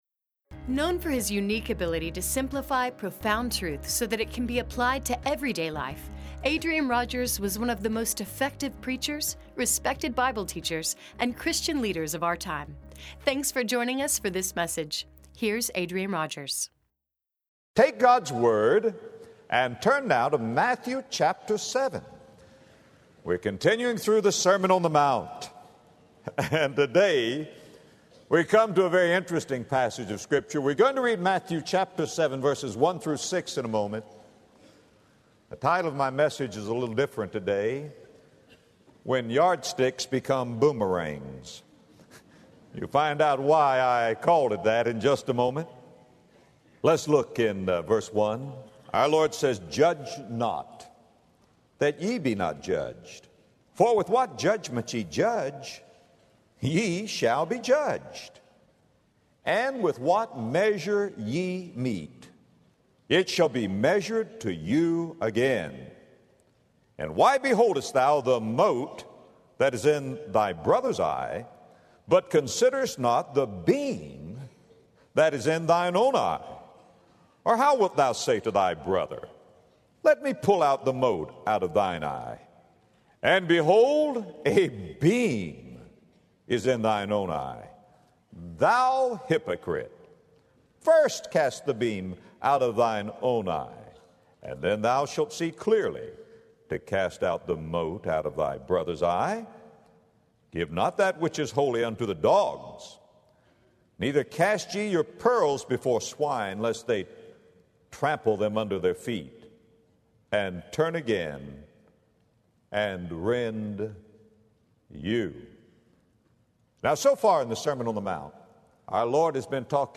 We all have our tape measures and yardsticks, but in Matthew 7:1-6, Jesus says that a yardstick will become a boomerang. In this message, Adrian Rogers gives a word of warning to those who judge others harshly and offers insight on how to discern right and wrong through merciful eyes.